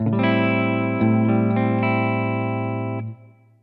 accord de septième
Am7.mp3